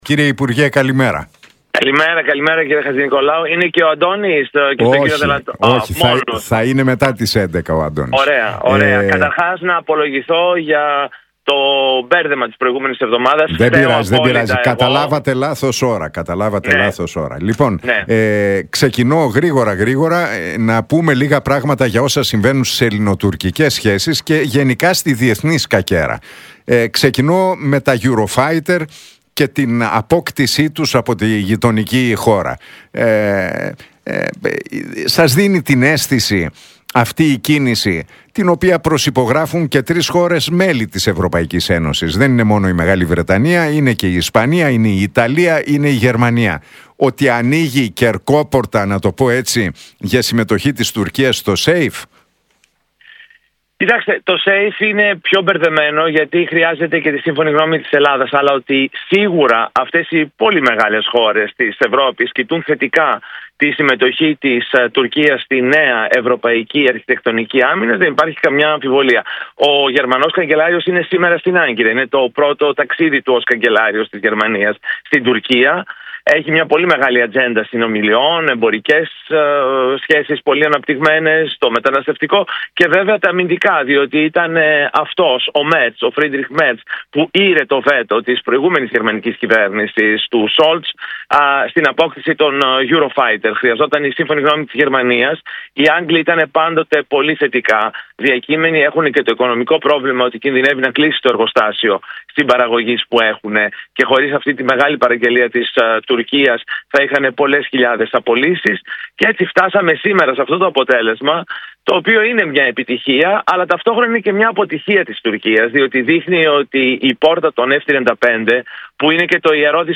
Για την Τουρκία και την αγορά των αεροσκαφών Eurofighter, τις εξελίξεις στην ανατολική Μεσόγειο και τη Γάζα μίλησε ο κοινοβουλευτικός εκπρόσωπος της ΝΔ, Δημήτρης Καιρίδης στον Νίκο Χατζηνικολάου από τη συχνότητα του Realfm 97,8.